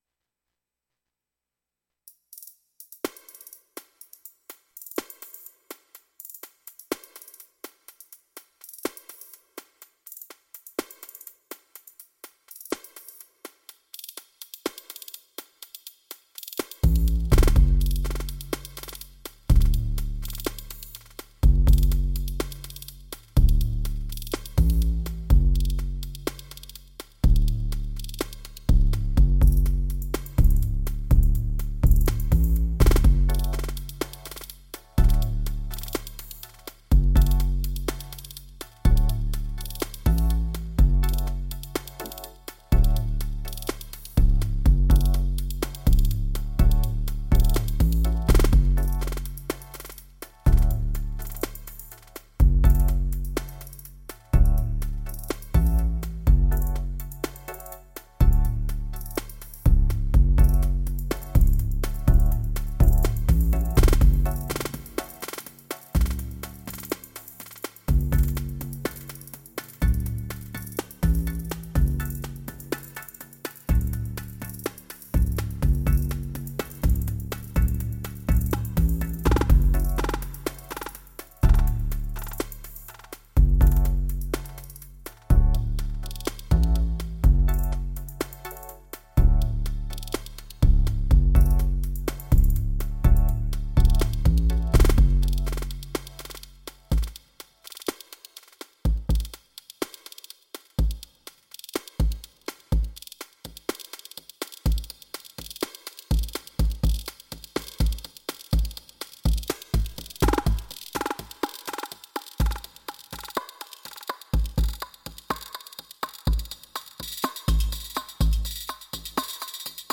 Testing the TRK All function (tweak filter + effects + + ) on a one pattern loop + playing with mutes.
BD Modern + BD hard (double kick)
Sd basic + UT impulse (double snare)
CY Alloy + CH Classic (double HH)
SY Chord
BD FM (Bass - found in sound list)
All sounds (except bass) tweaked from original
Everything except Bass, Delay and Rev through Filter FX
No external processing - only Syntakt - stereo in audio to daw
Proper dub! Damn.